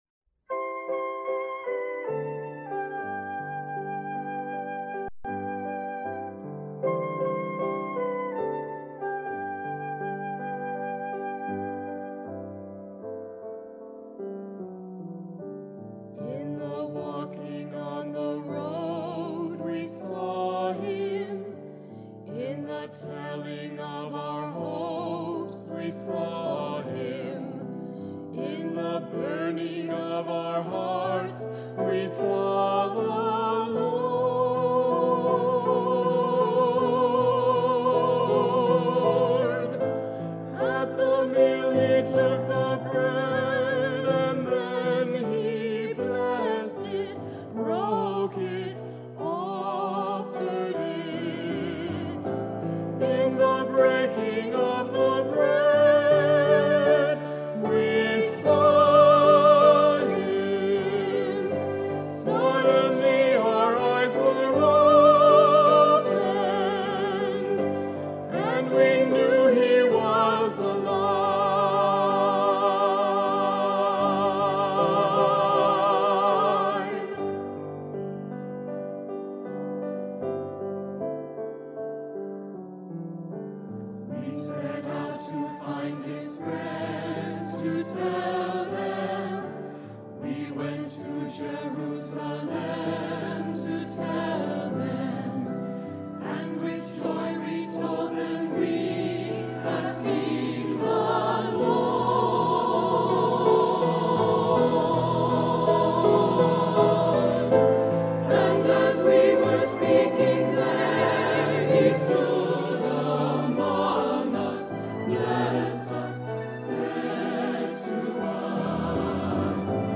Voicing: SATB; Descant; Assembly